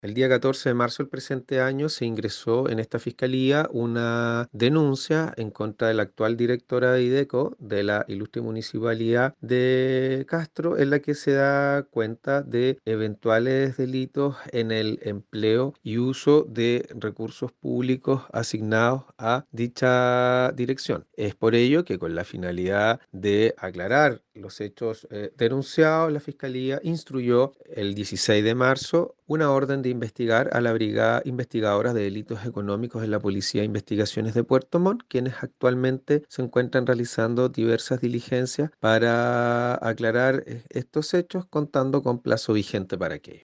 Respecto a la investigación en curso, se refirió el Fiscal de Castro, Fernando Metzner: